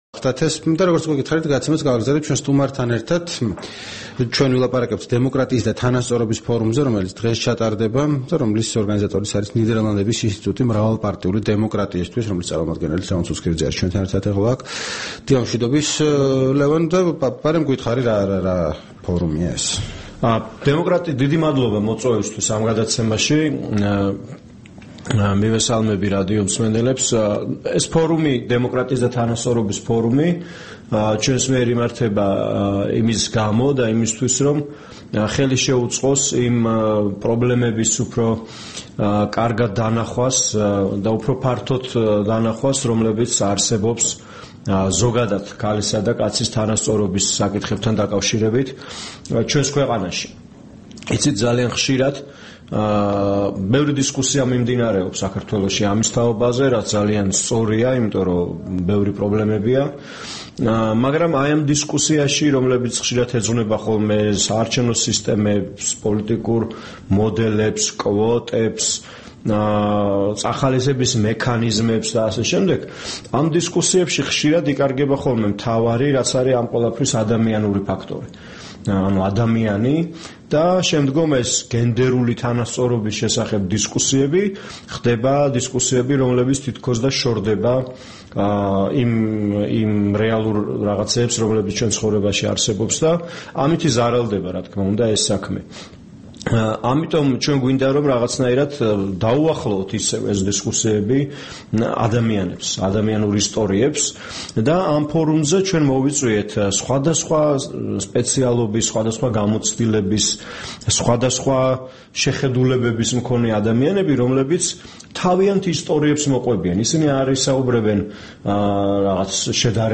რადიო თავისუფლების თბილისის სტუდიაში სტუმრად იყო
საუბარი